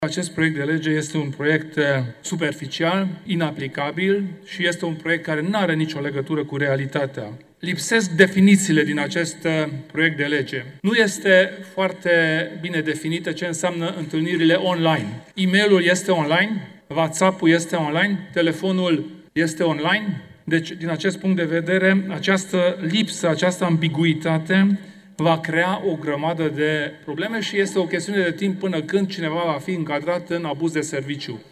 Kelemen Hunor, liderul UDMR: „Lipsesc definițiile din acest proiect de lege. Nu este foarte bine definit ce înseamnă întâlnirile online”